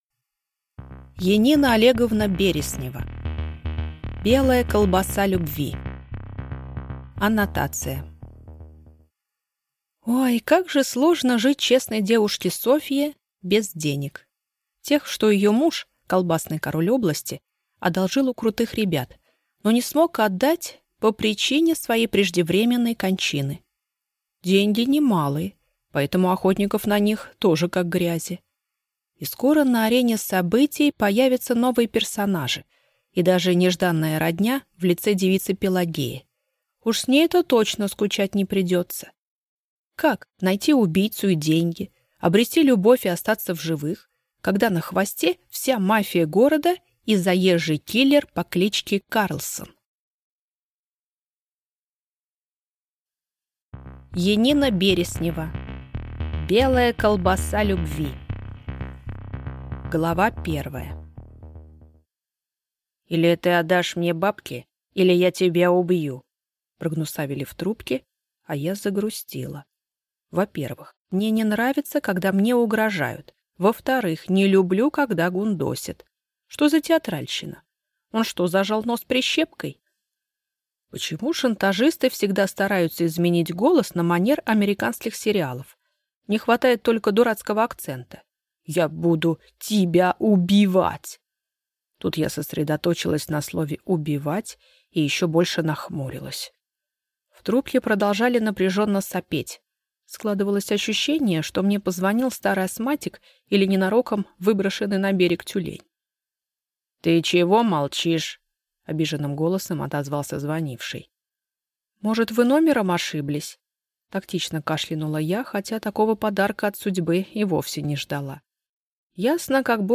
Аудиокнига Белая колбаса любви | Библиотека аудиокниг